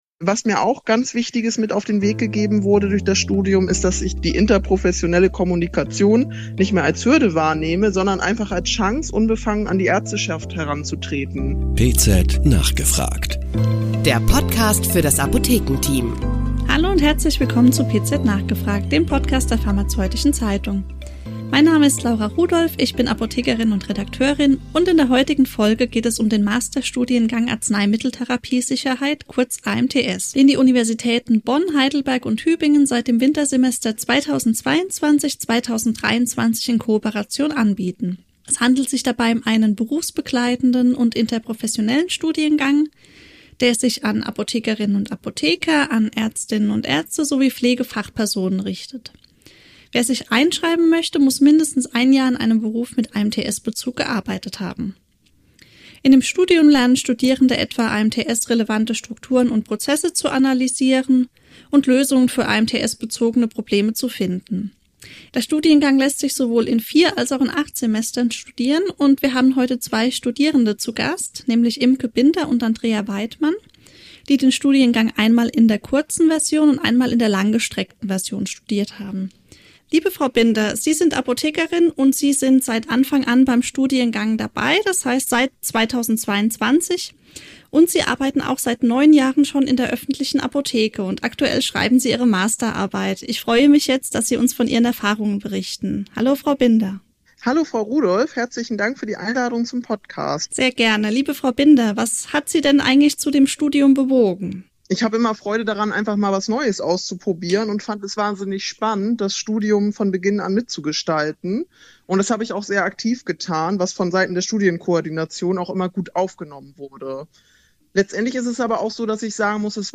Zwei Studierende berichten von ihren Erfahrungen mit dem interprofessionellen Masterstudiengang Arzneimitteltherapiesicherheit.